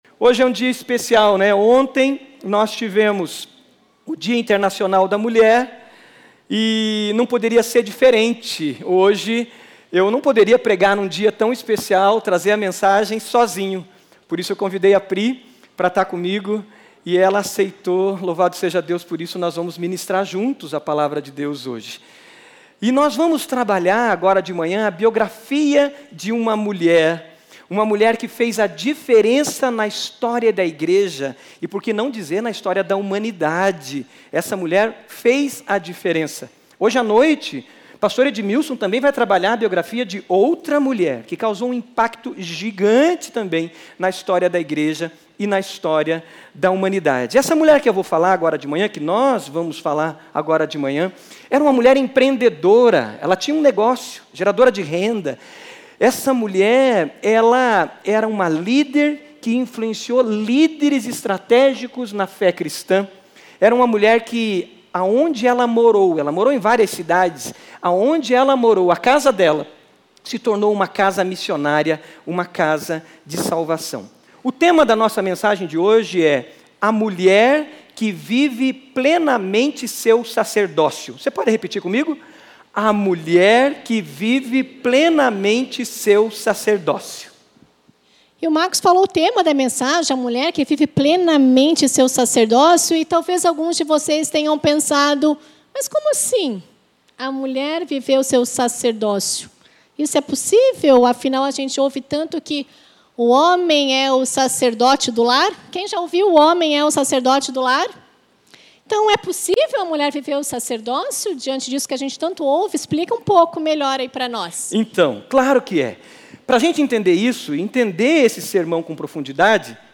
Mensagem
na Igreja Batista do Bacacheri.